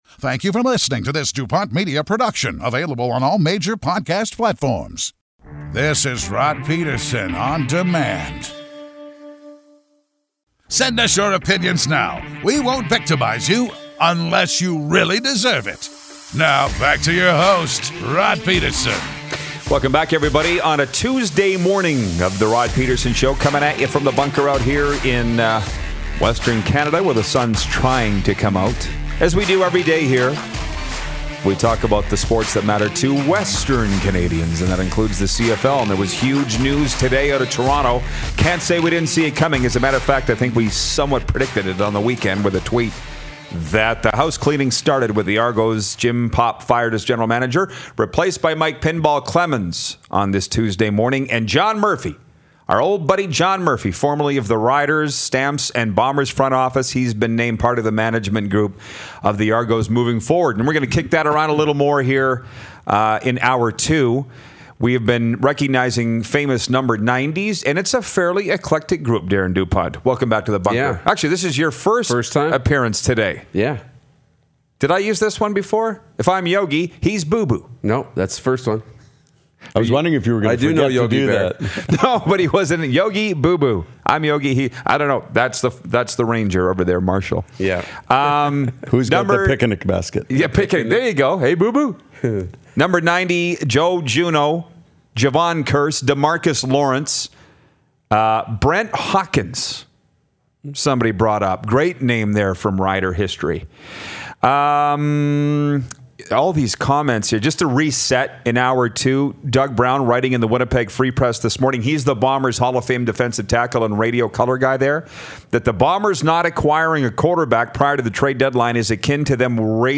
Coffee is brewing, and so is sports talk!